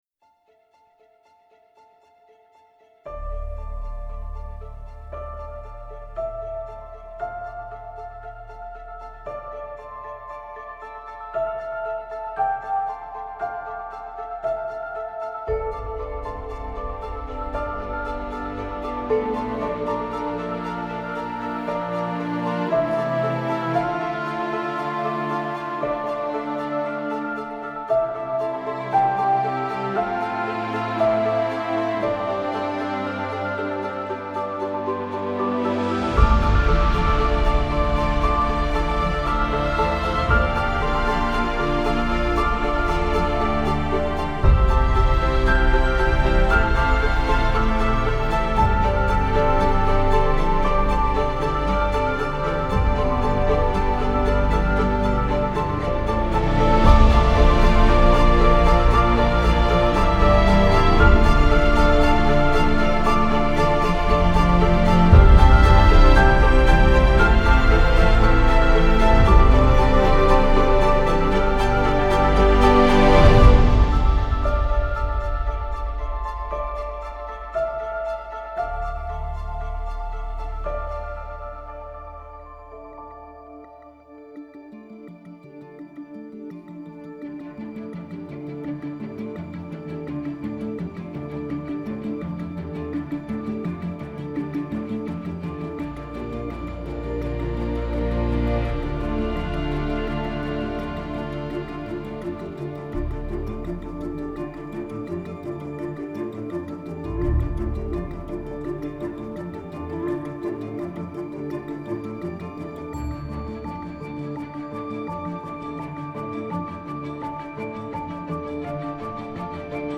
موسیقی متن موسیقی بیکلام